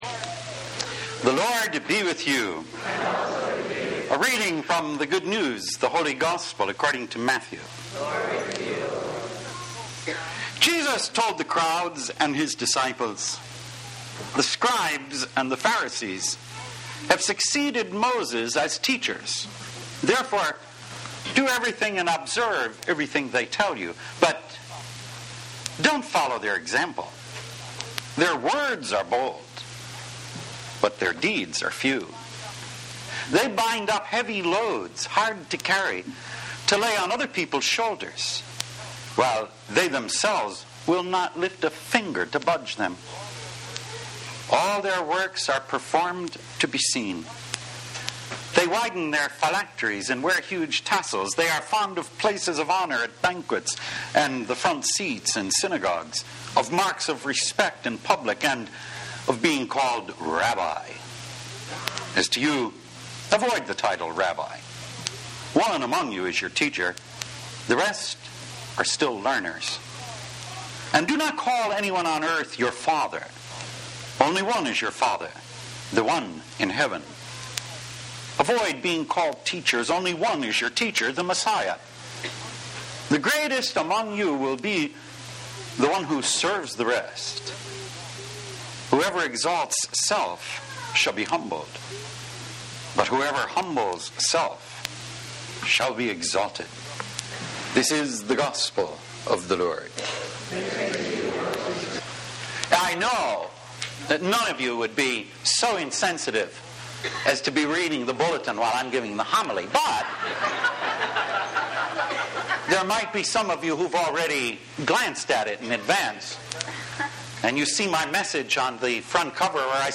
31st Sunday in Ordinary Time Posted on November 5, 2017 Updated on November 5, 2017 Originally delivered on October 31, 1993 Readings: Malachi 1:14-2:2, 8-10; Thessalonians 2:7-9, 13; Matthew 23:1-12 In this homily